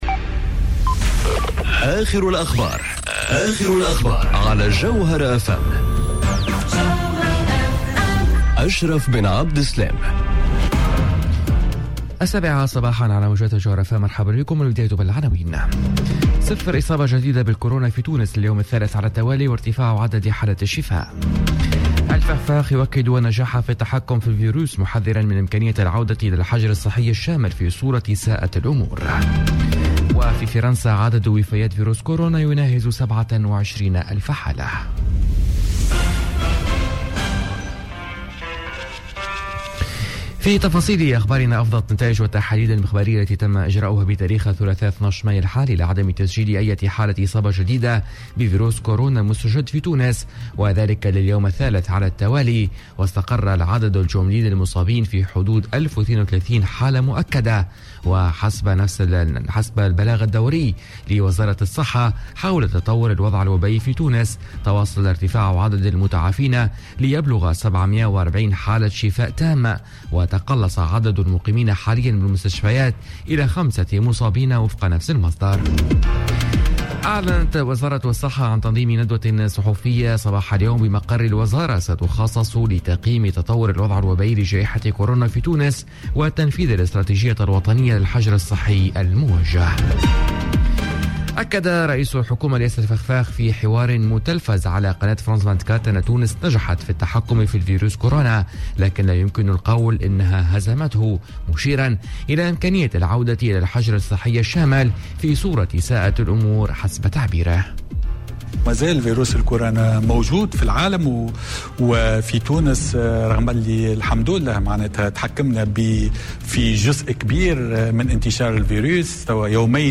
نشرة أخبار السابعة صباحا ليوم الإربعاء 13 ماي 2020